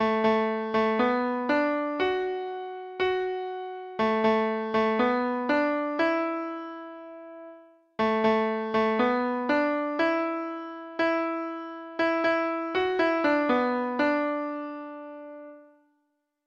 Traditional Trad. Tom Dooley Treble Clef Instrument version
Folk Songs from 'Digital Tradition' Letter T Tom Dooley